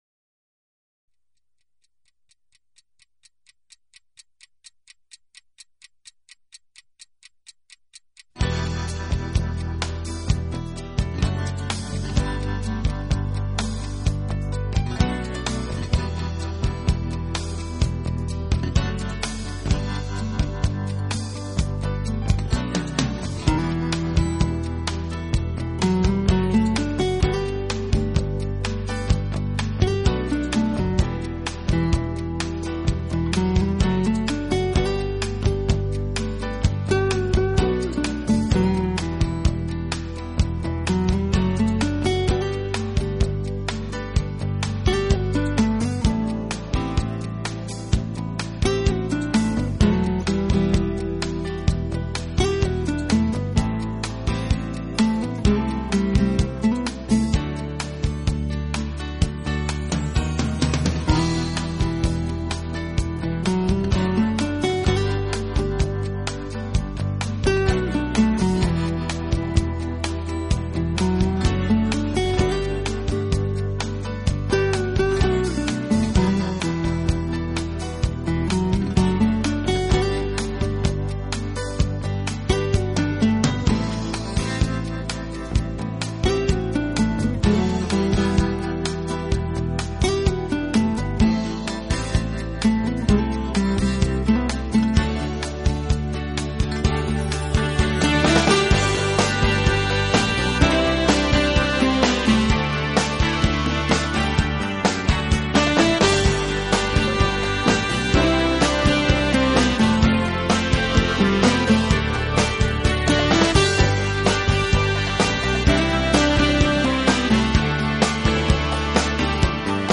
整体来看，这是一张现代爵士的专辑唱片
清新明快的节奏可以使人一天都精神十足，更显对人生积极